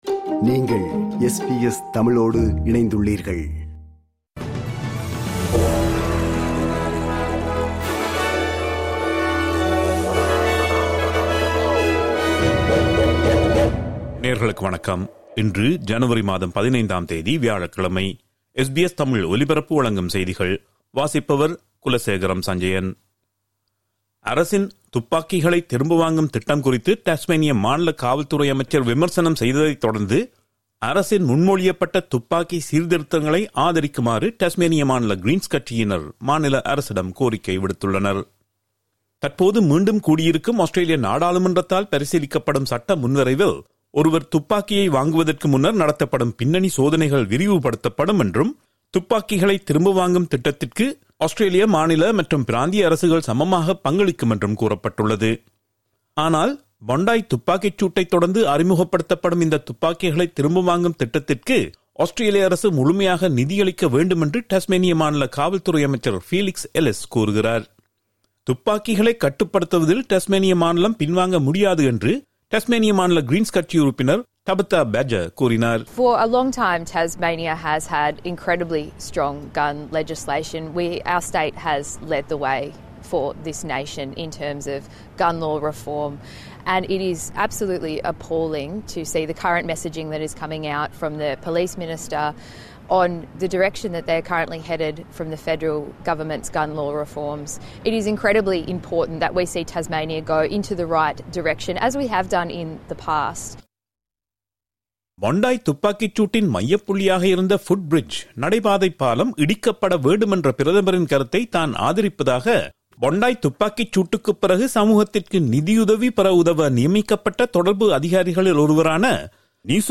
இன்றைய செய்திகள்: 15 ஜனவரி 2026 வியாழக்கிழமை
SBS தமிழ் ஒலிபரப்பின் இன்றைய ( வியாழக்கிழமை 15/01/2026) செய்திகள்.